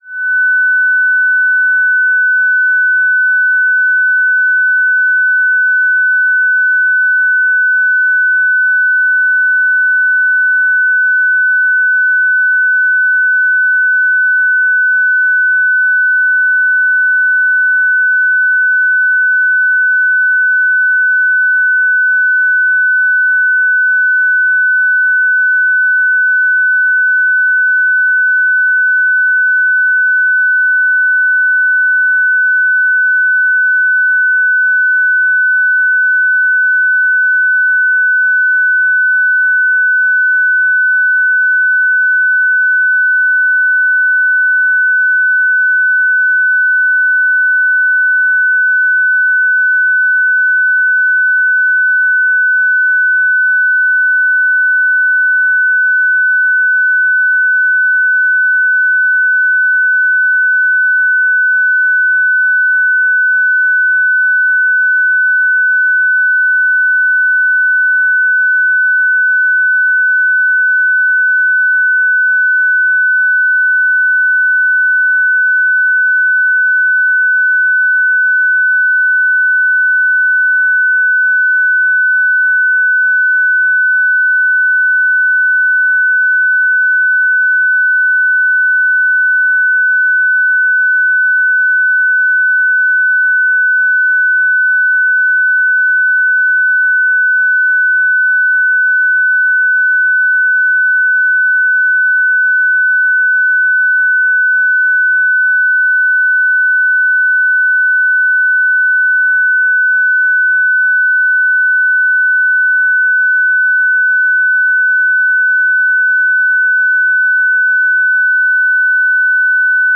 FST4, 300-sec mode
FST4-300.ogg